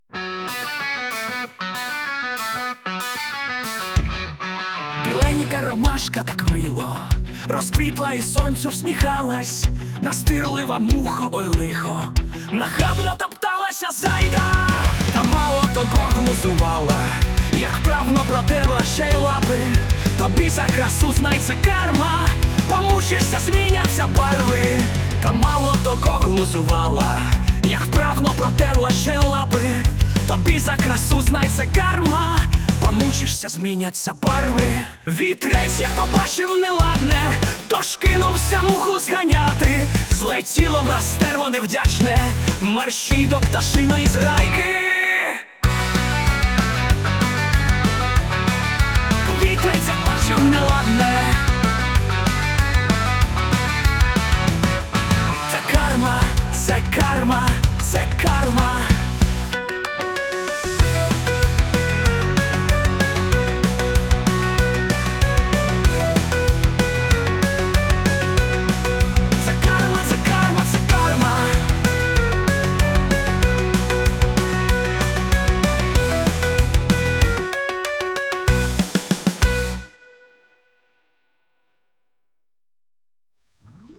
Музична композиція створена за допомогою SUNO AI
здивували роковою композицією ...